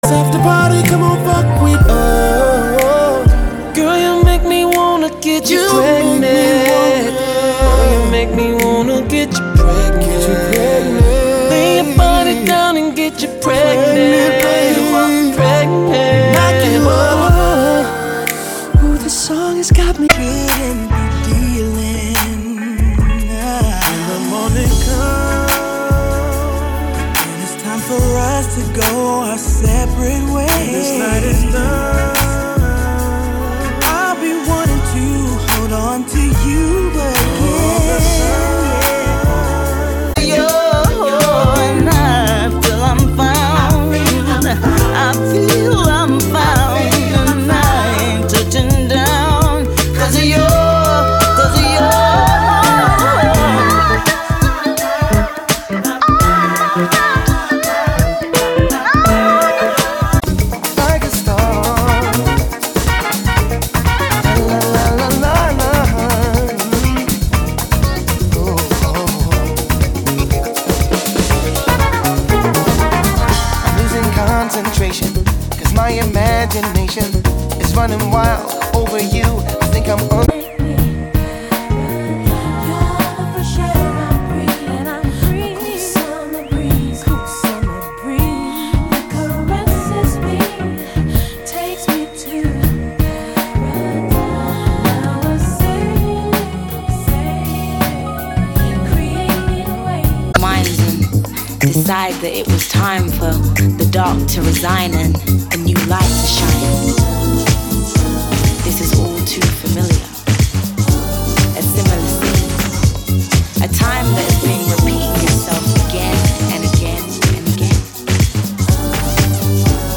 Focus is on soul and r&b this time.
Then some electronica to finish off.
MP3 sound bite (7 MB). 20 seconds from each song.